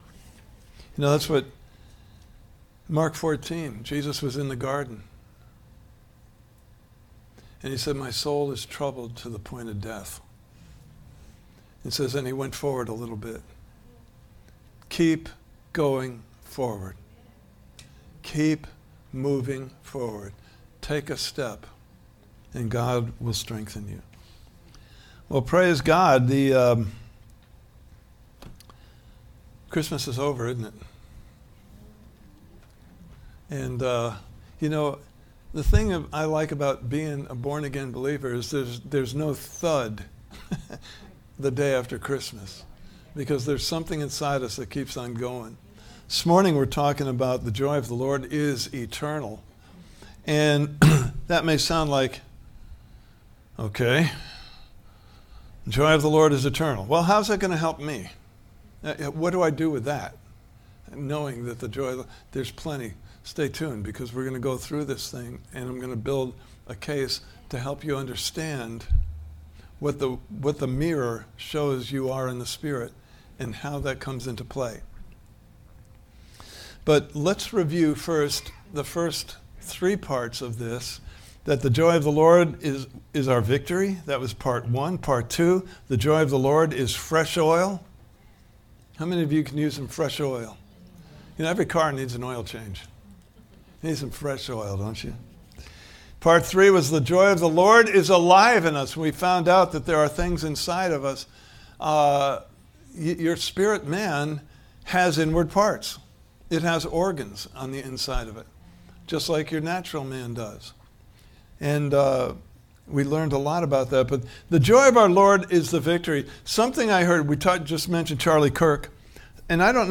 Series: The Wonderful Joy of the Lord! Service Type: Sunday Morning Service « Part 3: The Joy of the Lord is Alive in Us!